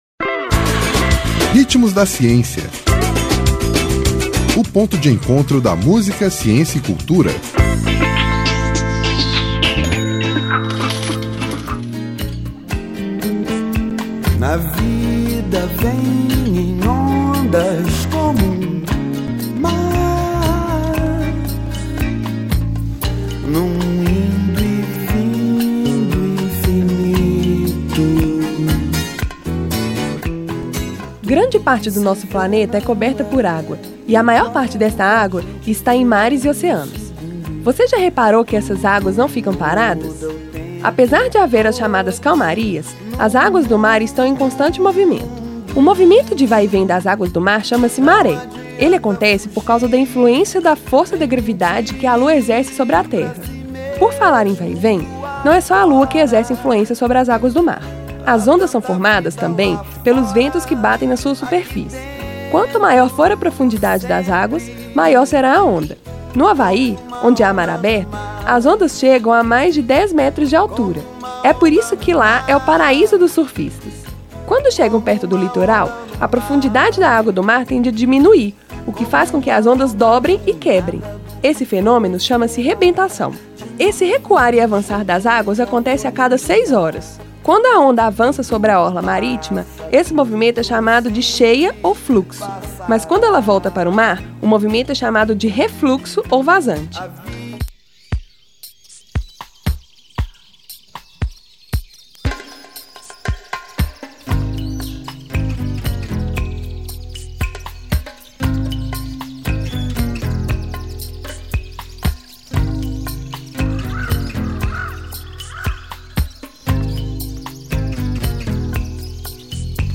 Intérprete: Lulu Santos